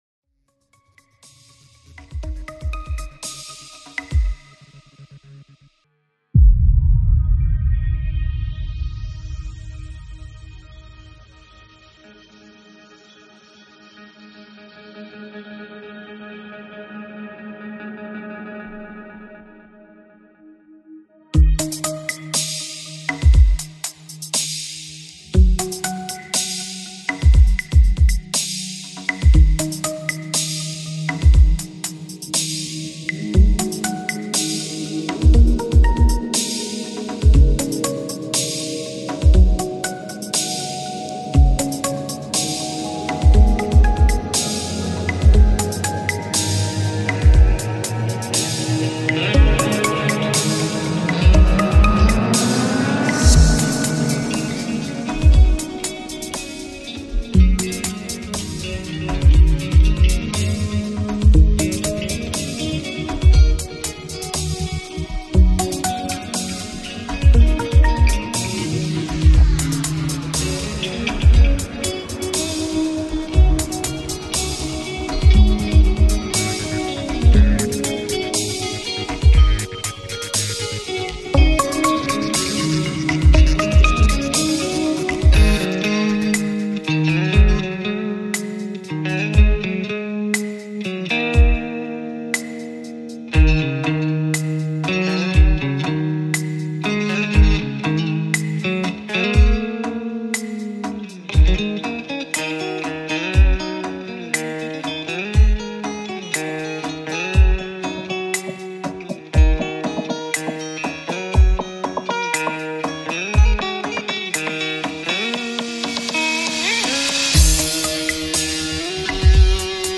Just a quick, not too long, ambient tune I threw together.